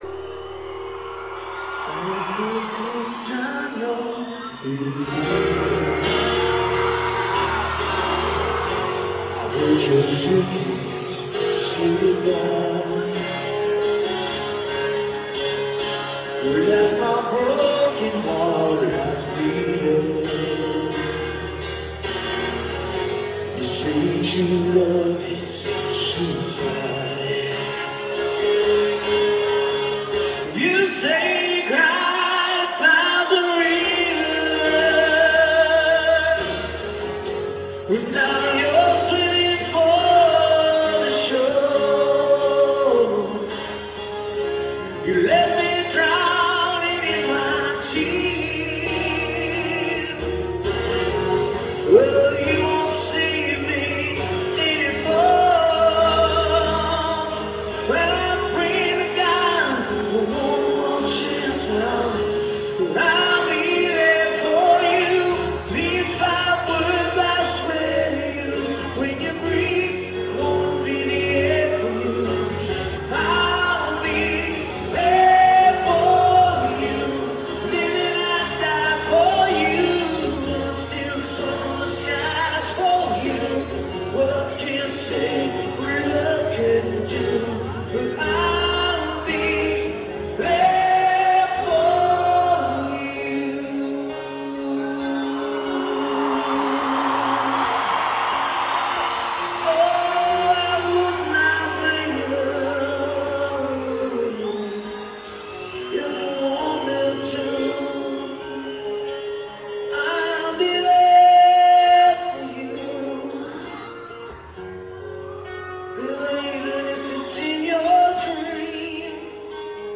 (Accoustic version)